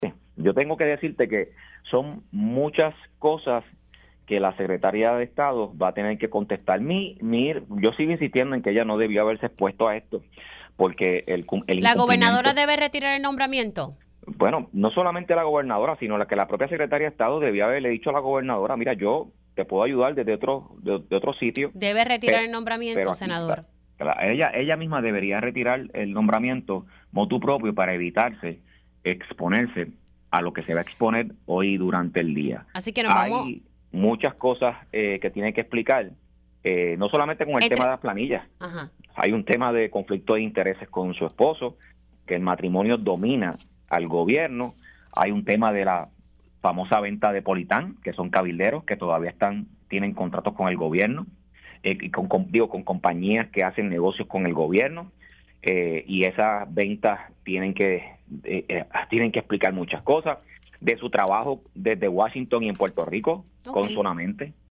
309-LUIS-JAVIER-HERNANDEZ-PORTAVOZ-PPD-SENADO-VERONICA-FERRAIOULI-DEBE-RETIRAR-SU-NOMBRAMIENTO-MOTU-PROPIO.mp3